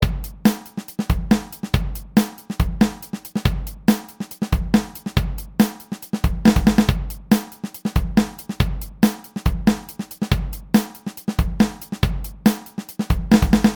After I rearranged my clips, I didn’t really like how it sounded, so I just sliced up the last beat in half and put one slice ahead of the second bass and snare drum slice.